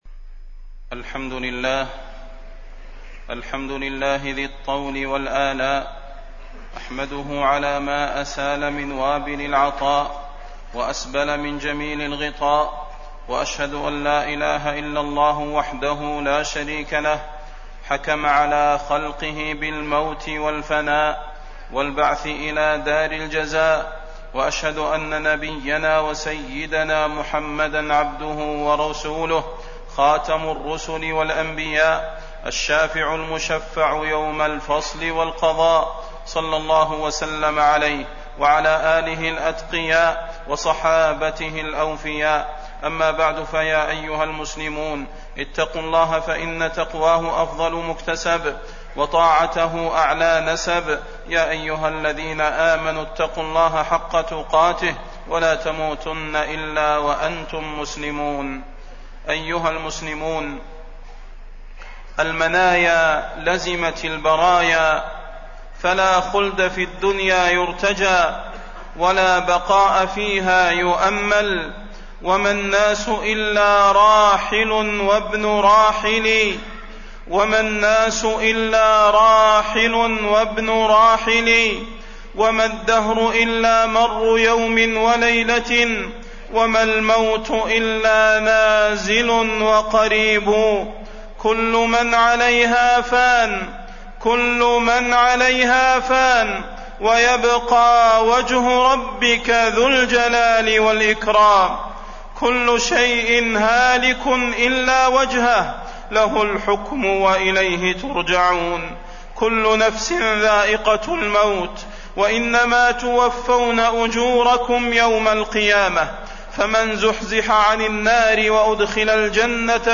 تاريخ النشر ٤ محرم ١٤٣٢ هـ المكان: المسجد النبوي الشيخ: فضيلة الشيخ د. صلاح بن محمد البدير فضيلة الشيخ د. صلاح بن محمد البدير الاعتبار بانقضاء الأعمار